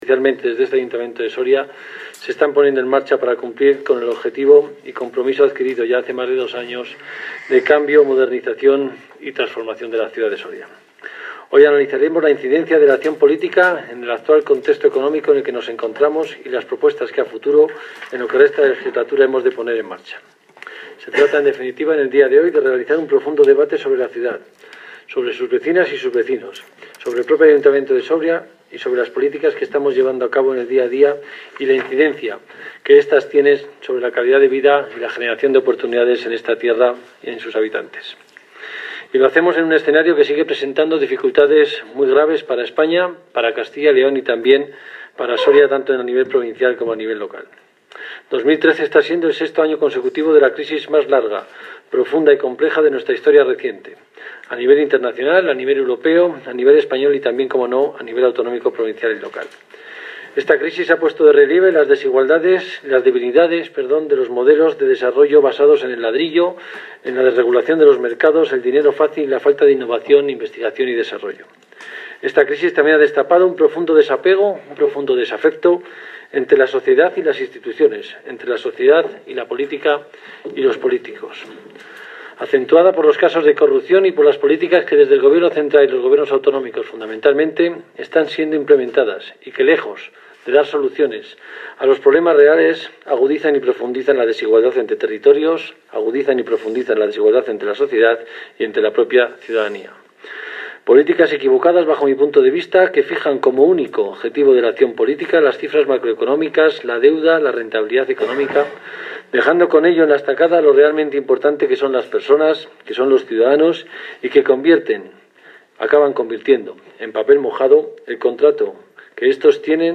AUDIO DEL DISCURSO DEL ESTADO DEL MUNICIPIO
intervencion-carlos-debate-estado-municipio.mp3